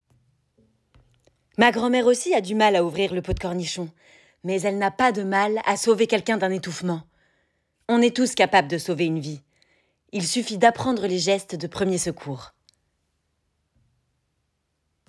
démo livre audio 4
- Mezzo-soprano Soprano